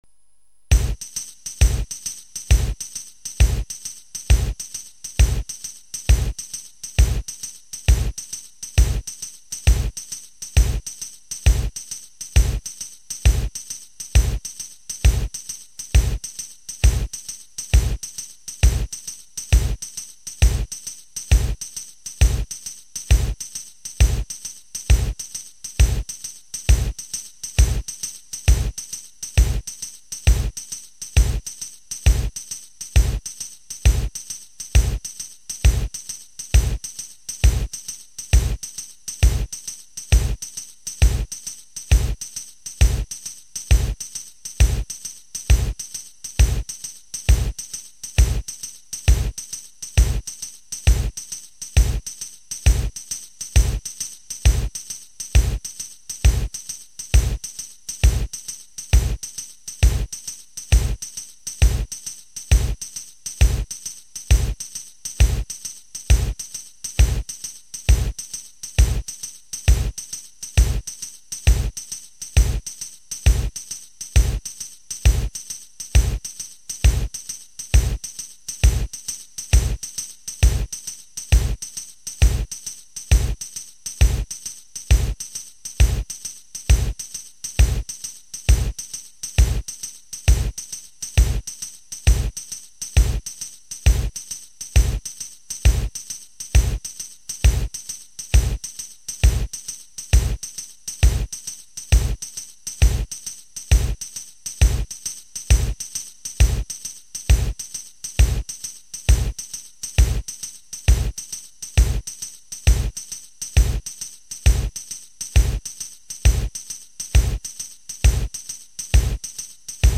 tarantella in sei ottavi
tre diverse basi ritmiche di tarantella
Tarantella in sei ottavi.MP3